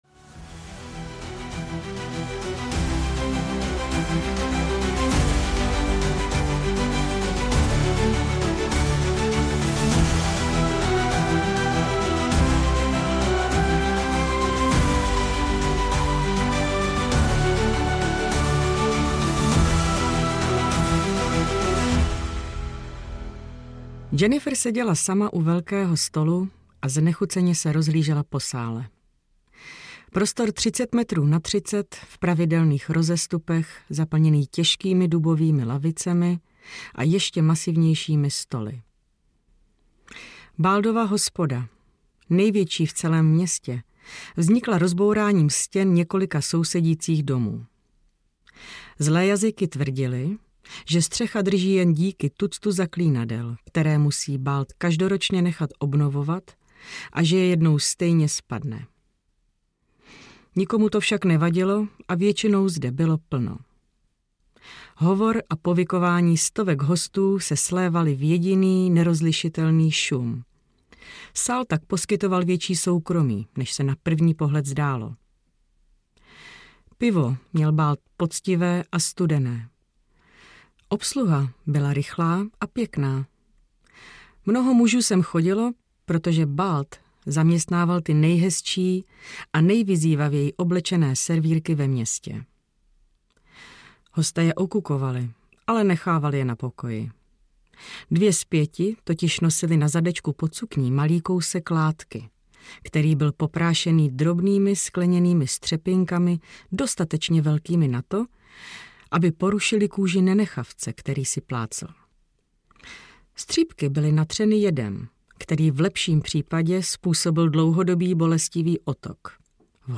JENNIFER – Důl Quake audiokniha
Ukázka z knihy